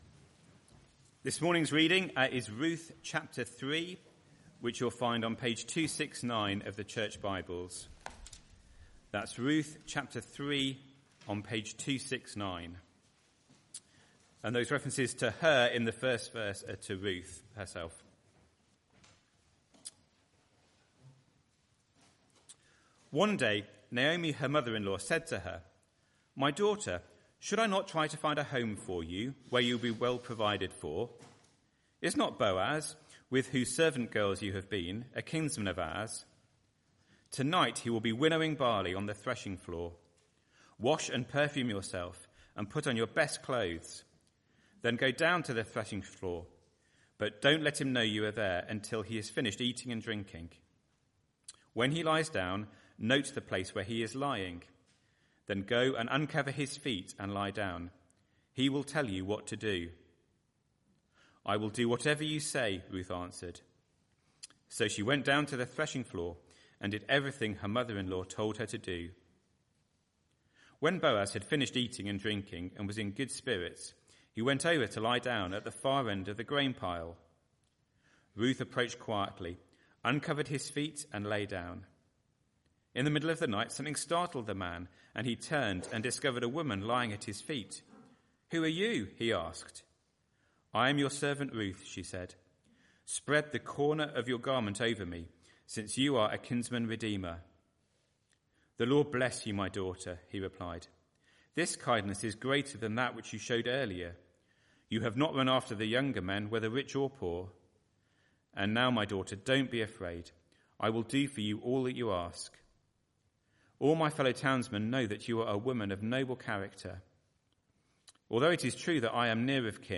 Arborfield Morning Service
Sermon